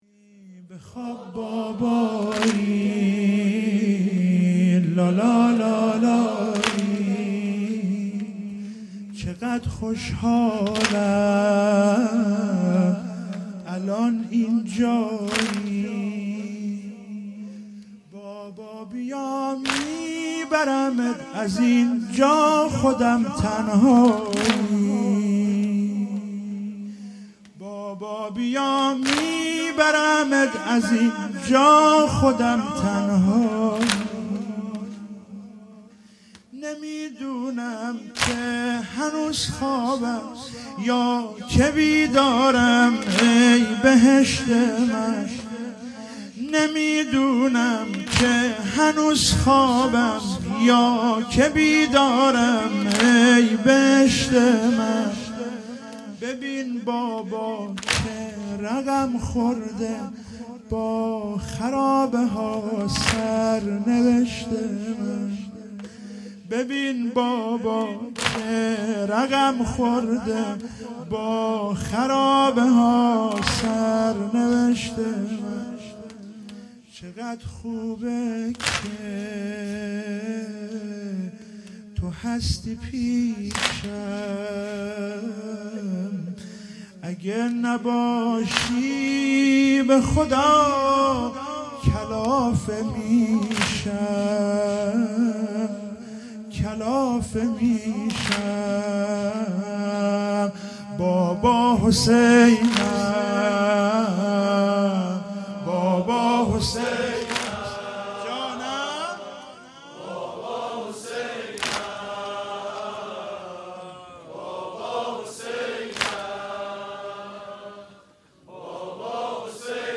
شب سوم محرم97 - واحد - بخواب بابایی لالالایی